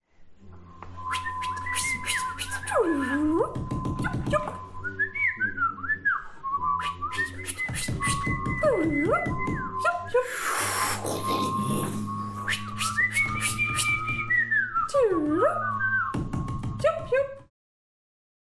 La proposition est adoptée avec les précisions suivantes : on forme des groupes de trois avec une seule partition à réaliser en commun.
Performance du Groupe 1. Extrait de 18” :